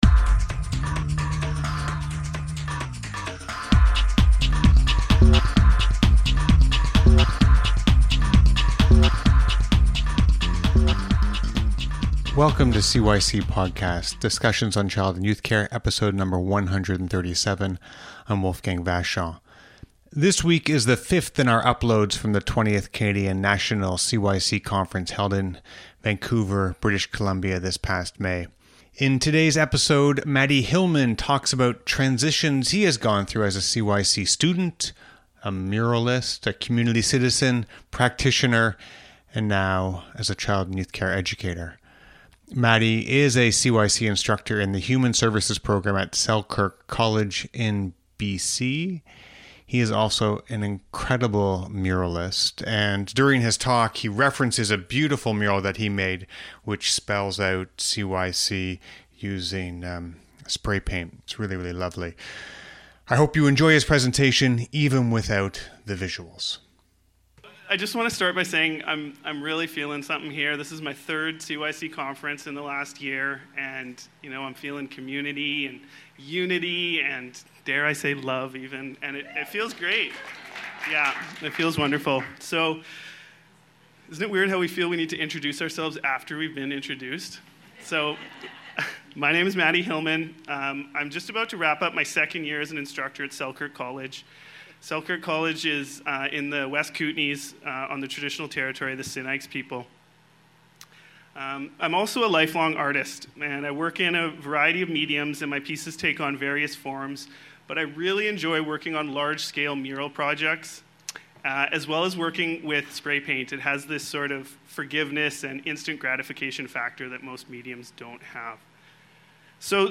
This week is the fifth in our uploads from the 20th Canadian National Child and Youth Care conference held in Vancouver, British Columbia this past May.